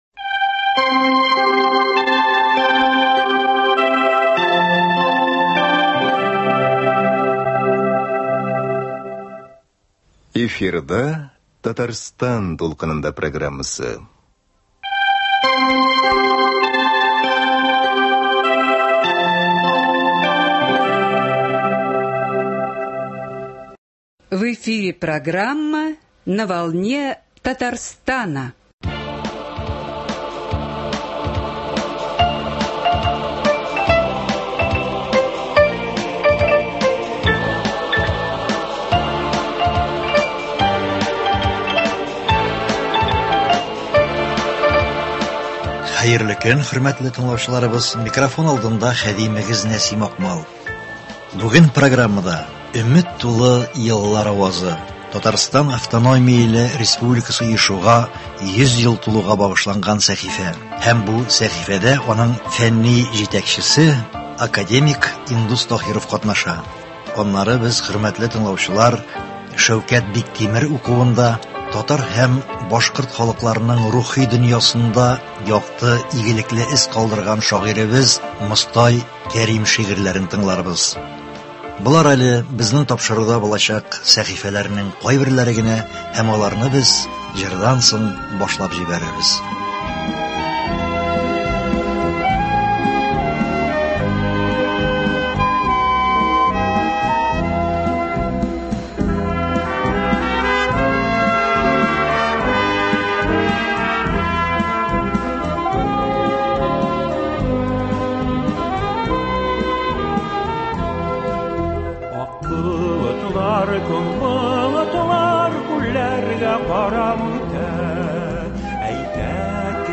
Татарстан автономияле республикасы оешуга 100 ел тулуга багышланган “Өмет тулы еллар авазы” сәхифәсендә аның фәнни җитәкчесе академик Индус Таһиров катнаша. Әңгәмәдә сүз ТАССР оешу нәтиҗәсендә татар халкының әдәбиятына, сәнгатенә, мәгарифенә яңа мөмкинлекләр ачылу һәм 20 нче елларның икенче яртысында туган авырлыклар турында.
Тапшыруның икенче өлешендә татар, башкорт халыкларының рухи дөньясында якты, игелекле эз калдырган шагыйрь Мостай Кәрим шигырьләре Шәүкәт Биктимеров язмасында тәкъдим ителә.